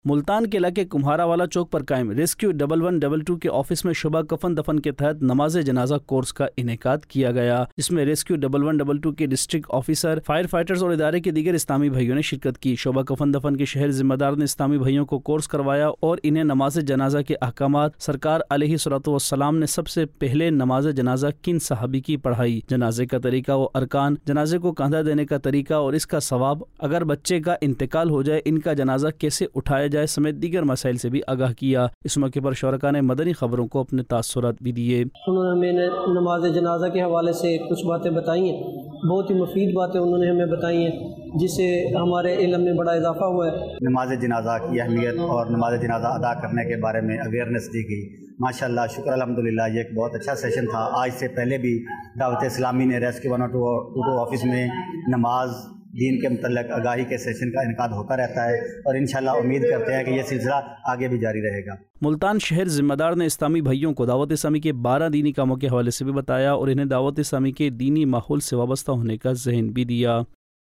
News Clips Urdu - 14 September 2023 - Shoba Kafan Dafan Kay Tehat Rescue 1122 Kay Office Mein Namaz e Janaza Course Ka Iniqad Nov 9, 2023 MP3 MP4 MP3 Share نیوز کلپس اردو - 14 ستمبر 2023 - شعبہ کفن دفن کے تحت ریسکیو 1122 کے آفس میں نمازِ جنازہ کورس کا انعقاد